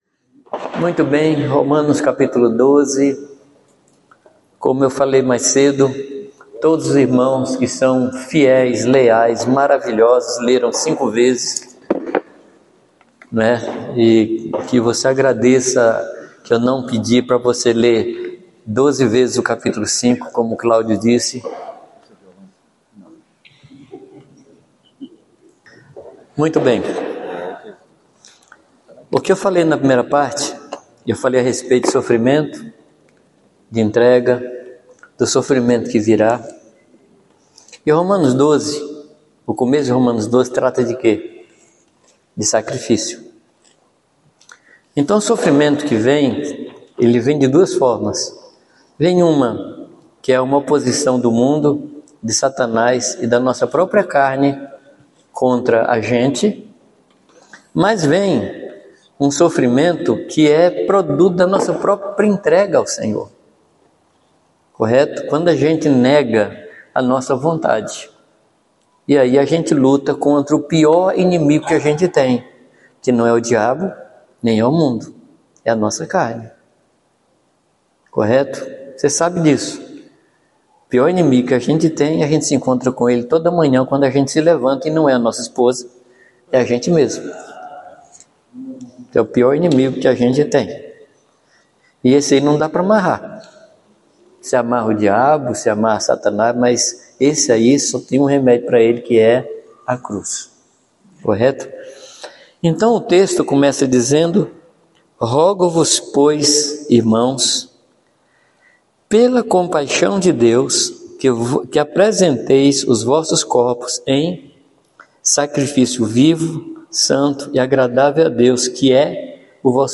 Encontro de Pastores 2022